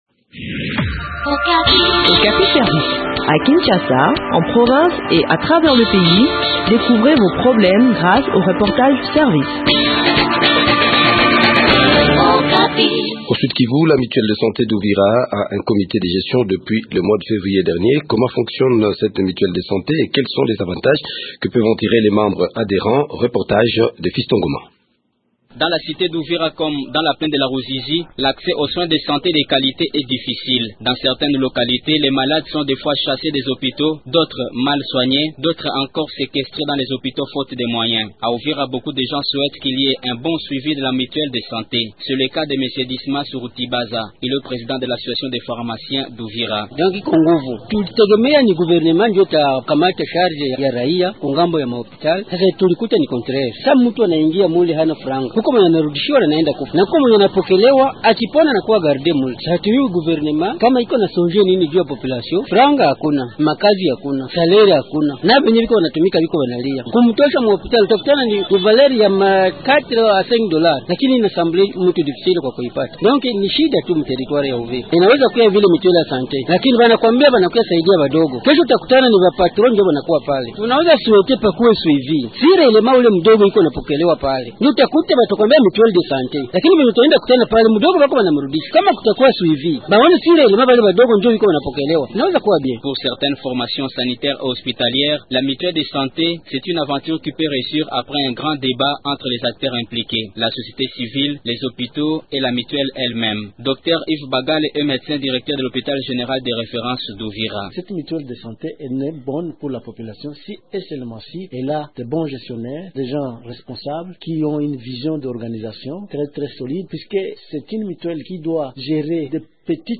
Le point sur l’organisation des activités de cette mutuelle de santé dans cet entretien